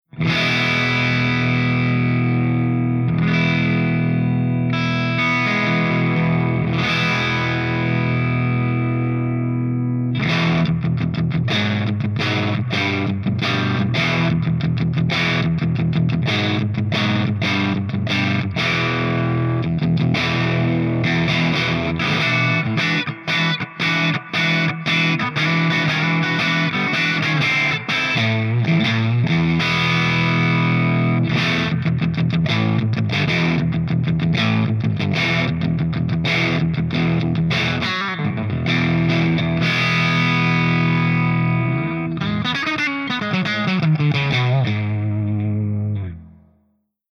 101_HIWATT_HIGHDRIVE_GB_P90.mp3